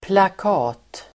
Uttal: [plak'a:t]